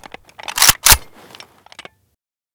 ak74m_unjam.ogg